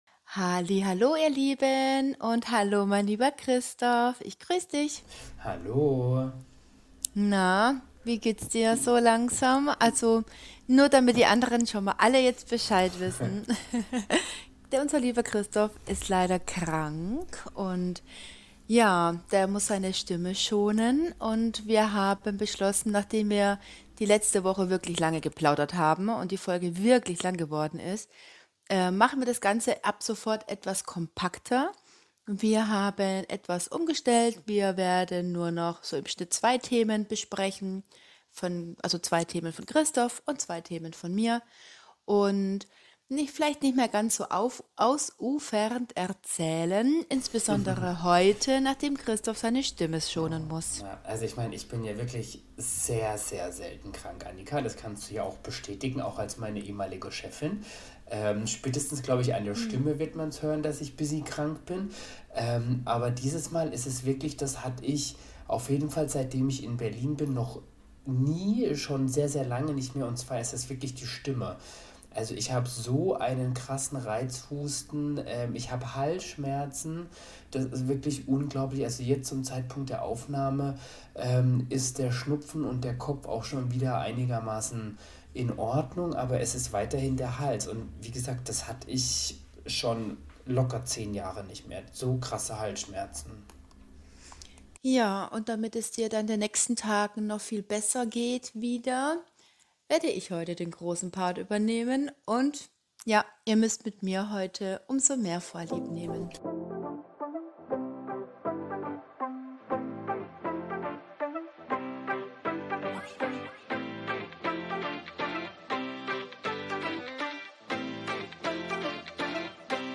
Beschreibung vor 7 Monaten Stillschweigen?! – diesmal klingt unser Podcast etwas anders.